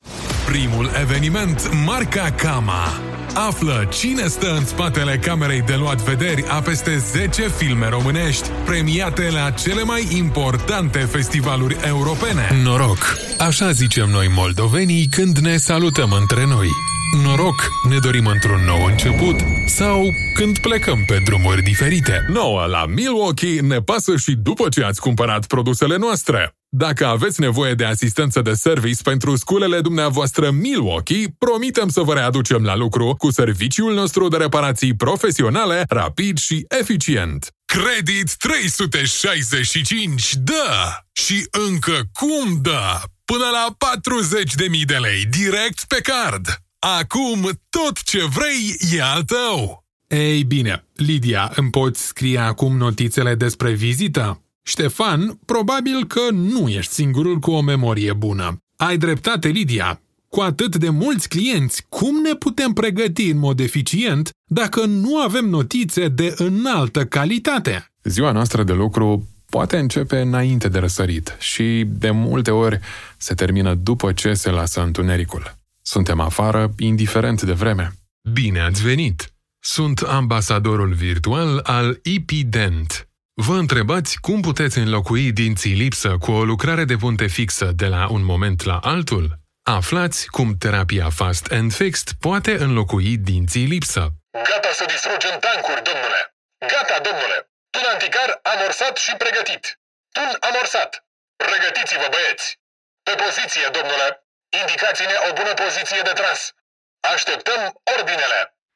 Experienced Romanian male voiceover artist
Passionate
Energetic
Happy